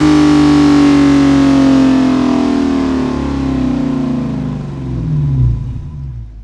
v8_05_Decel.wav